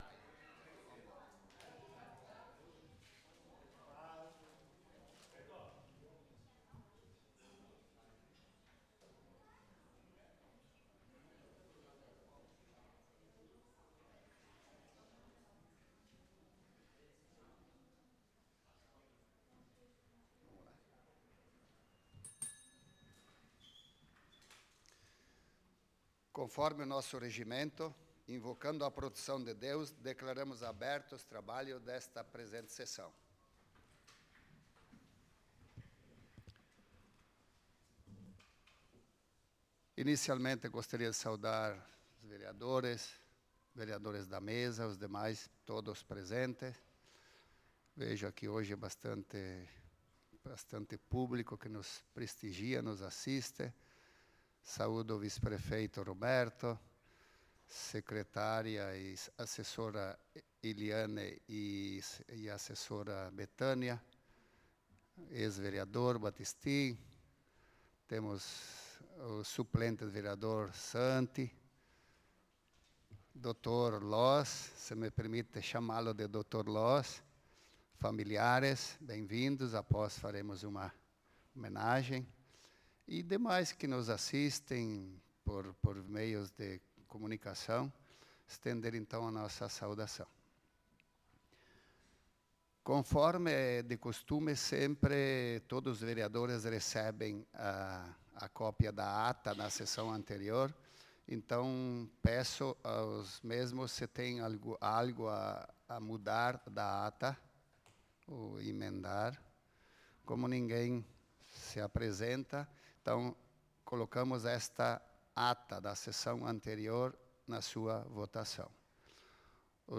Sessão Ordinária do dia 24/04/2024 - Câmara de Vereadores de Nova Roma do Sul
Sessão Ordinária do dia 24/04/2024